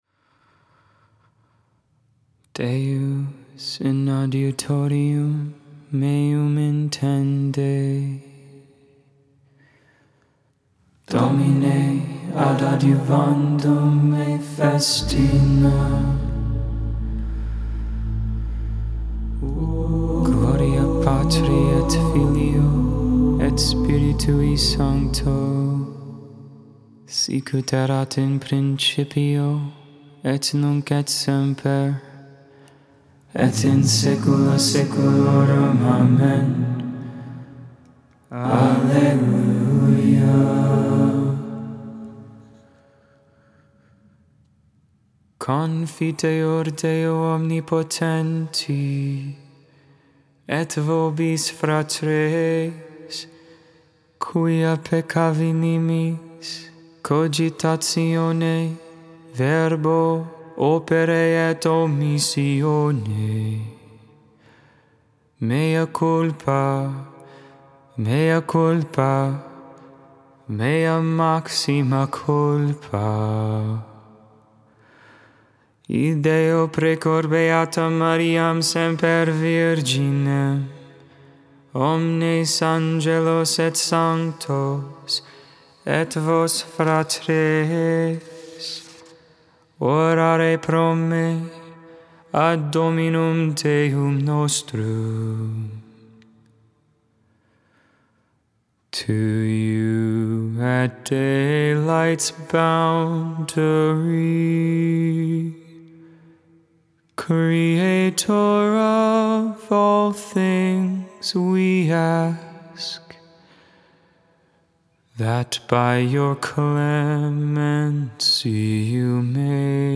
Tuesday Night Compline for Advent Season, 2021 (posted December 14th, 2021).
Canticle of Simeon: Luke 2v29-32 Concluding Prayer Benediction Alma Redemptoris Mater, Advent antiphon, Bl.